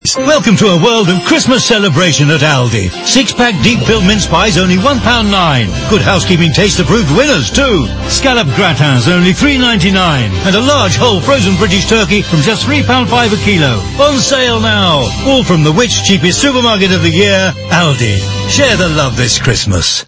It’s not just the consistent use of this well-known character that works so well for the brand, they’ve now been using the warm, expressive and unique voice of Jim Broadbent for the last 3 years.  Broadbent’s voiceover together with consistent music from the TV spot means this radio ad will be easily recognised by listeners, even with no carrots in sight.